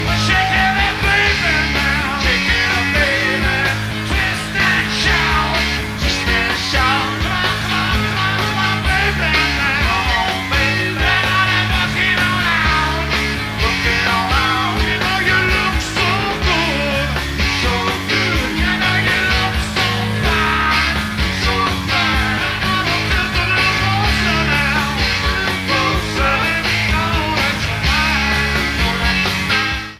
Pre-FM Radio Station Reels
Needs remastering.